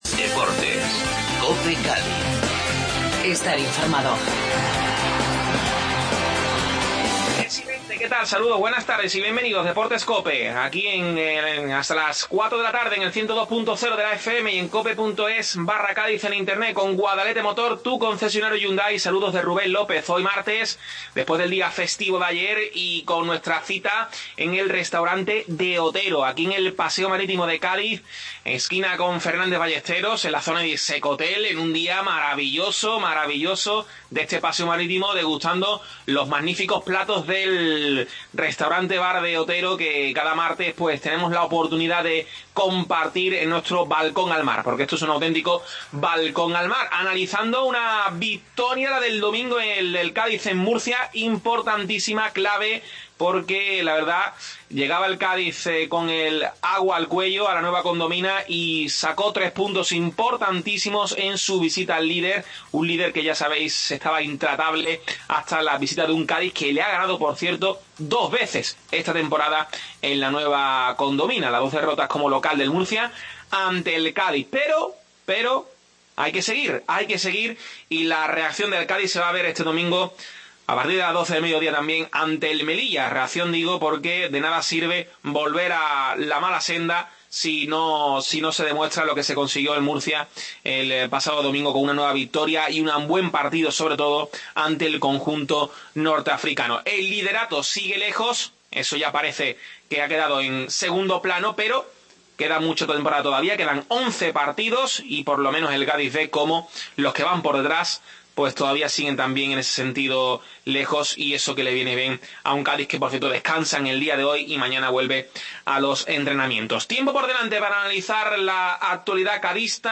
Desde el Restaurante De Otero tertulia